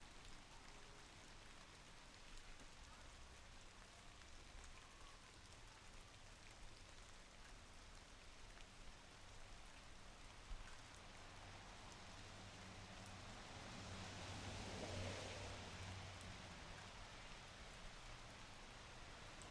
大雨
描述：克罗地亚暴雨
Tag: 雷雨 风暴 天气